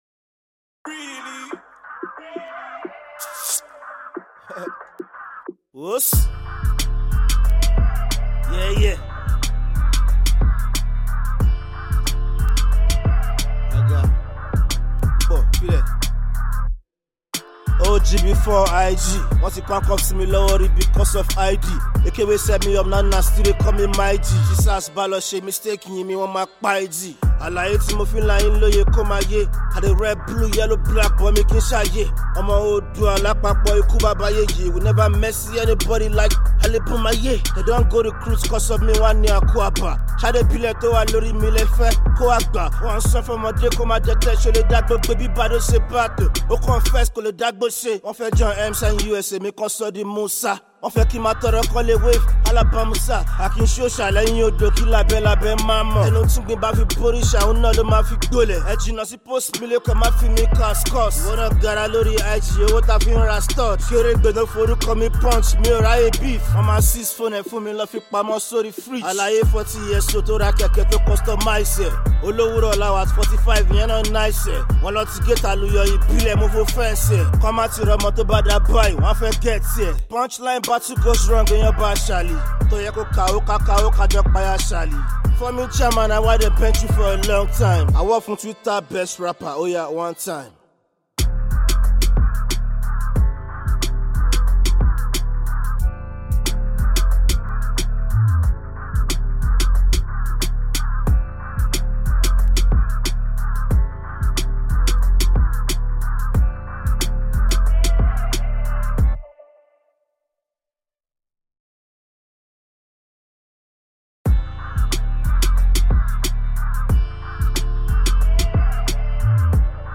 Indigenous rapper